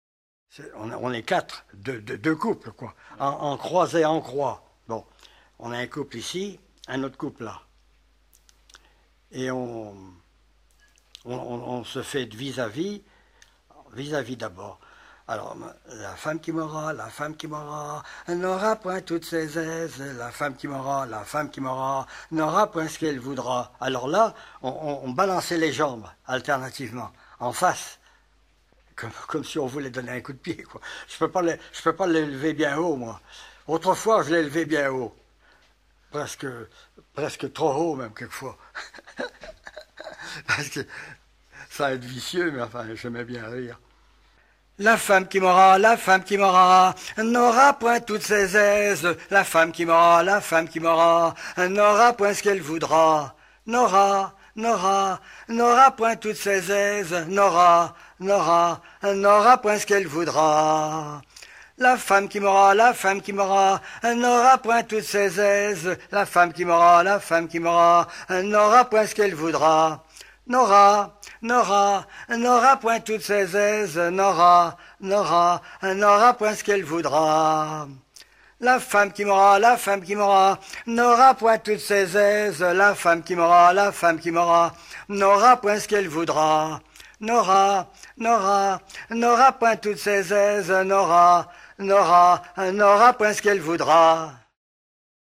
Chants brefs - A danser
Pièce musicale éditée